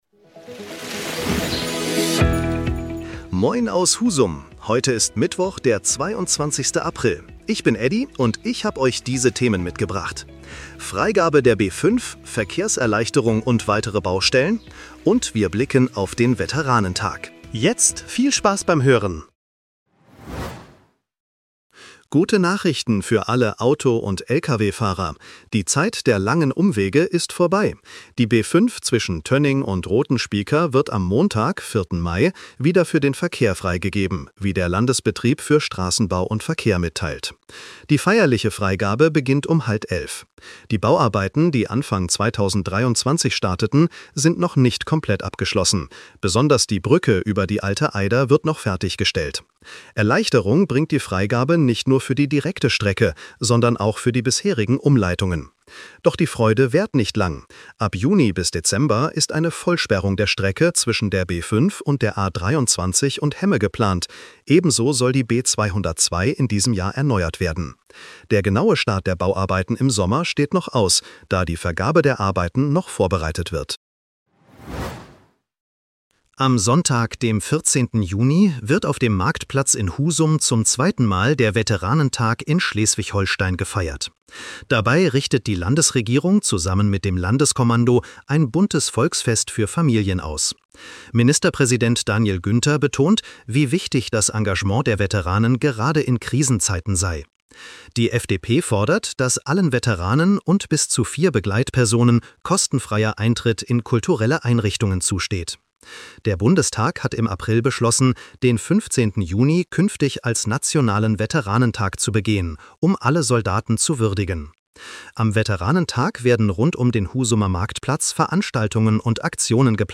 Was bewegt Husum heute? In unserem regionalen Nachrichten-Podcast